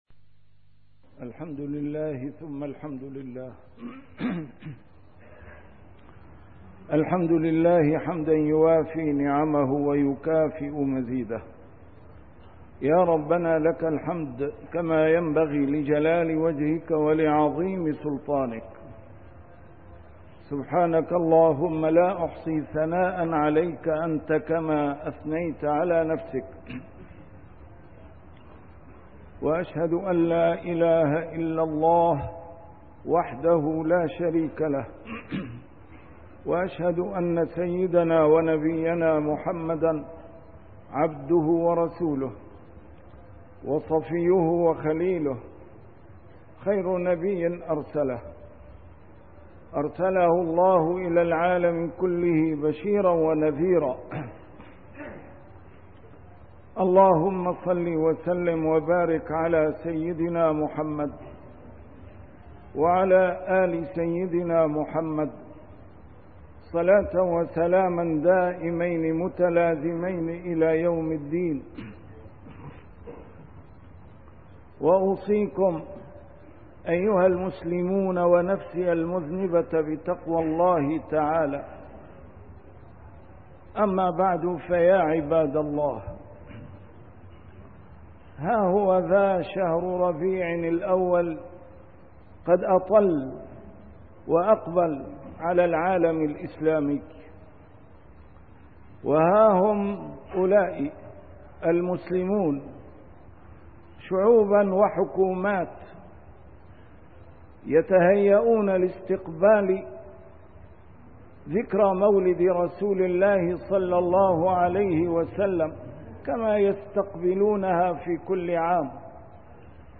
A MARTYR SCHOLAR: IMAM MUHAMMAD SAEED RAMADAN AL-BOUTI - الخطب - لهذا ينبغي أن نشعر بالخجل